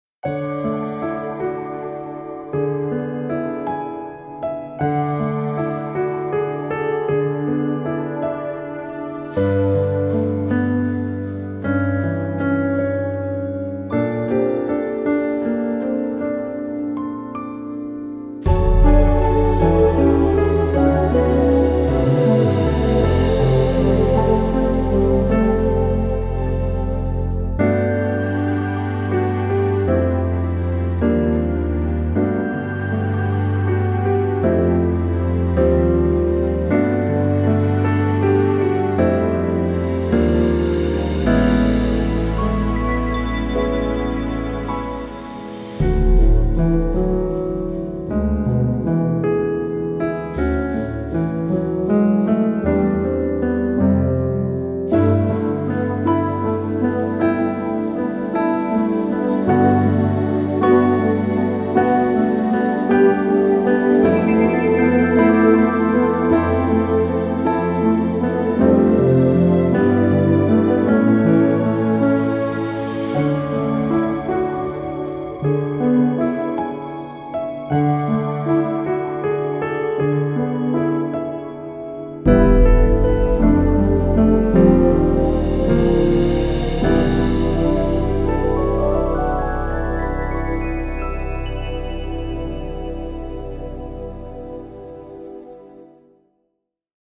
The two new BGMs.